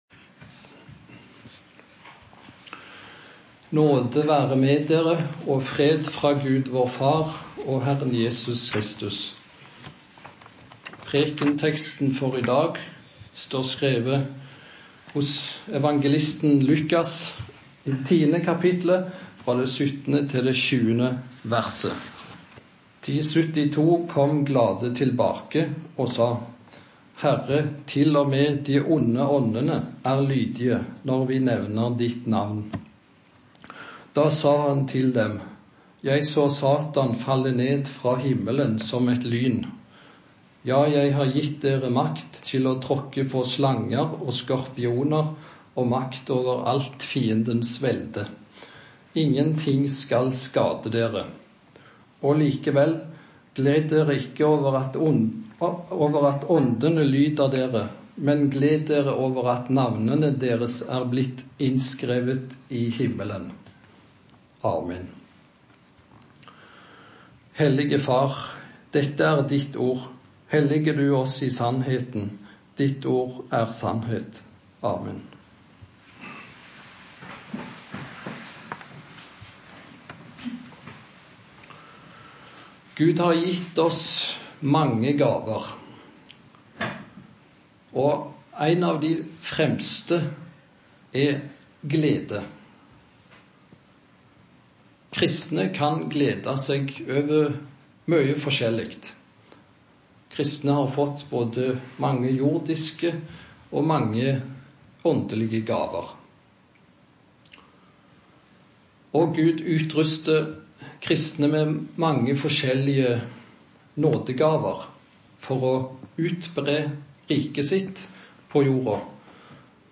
Preken på 1. søndag i faste